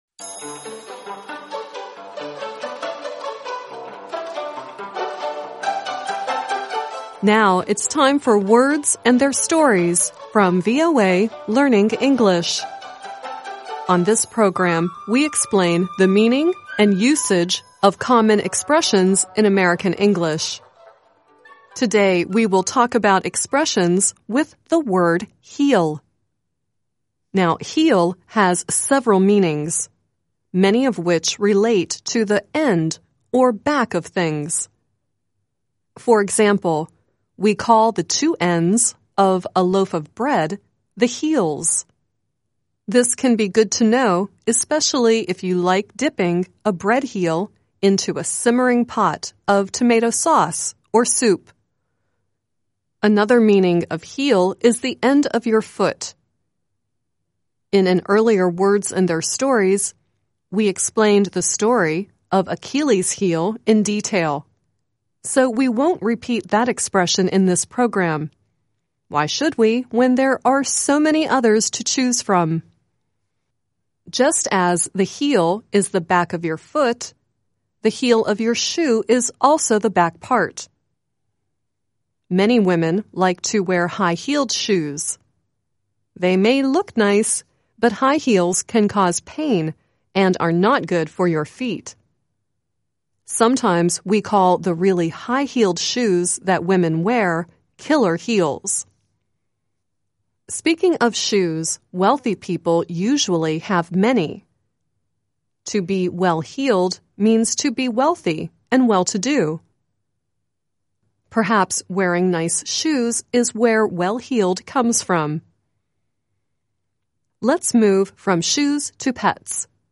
The song at the end is Jessica Mauboy singing "Kick Up Your Heels."